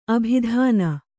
♪ abhidhāna